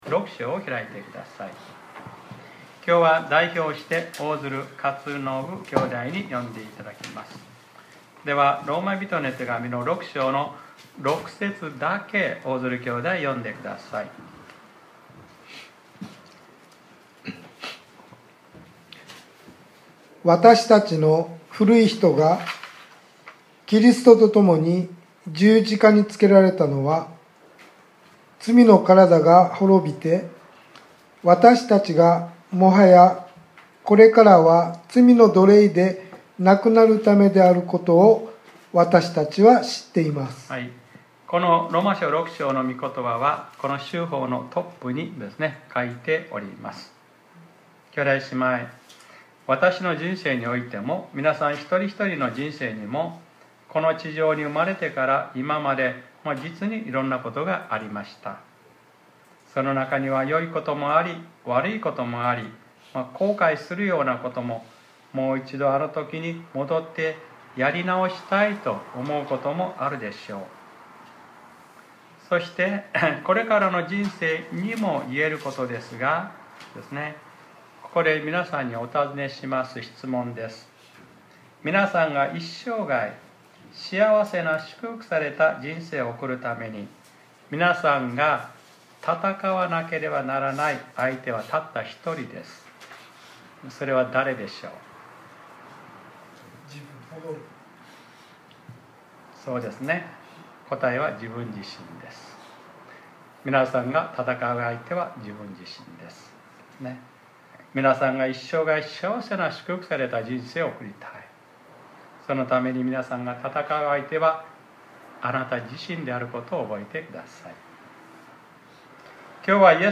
2021年11月07日（日）礼拝説教『 永続する祝福 』